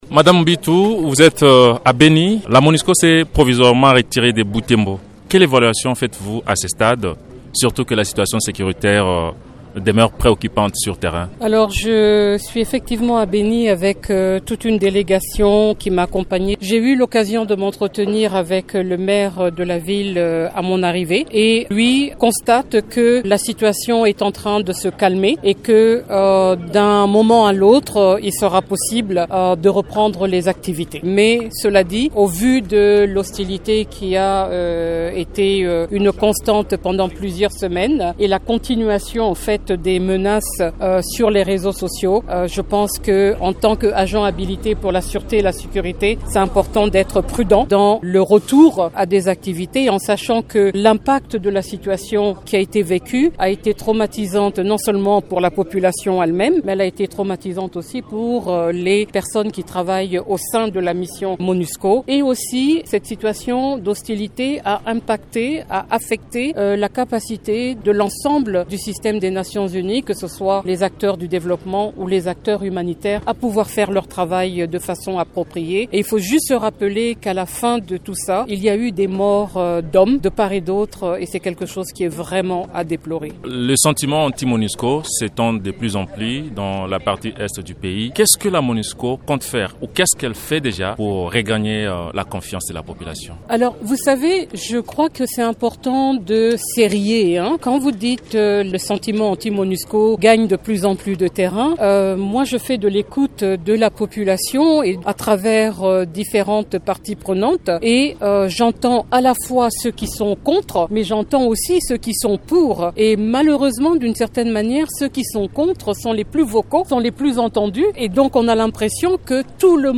S’adressant à la presse locale et internationale, la cheffe de la MONUSCO a déploré les incidents qui ont accompagné les manifestations anti-MONUSCO dans la région.